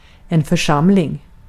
Ääntäminen
US : IPA : [ə.ˈsɛm.bli] UK : IPA : /əˈsɛmb.lɪi/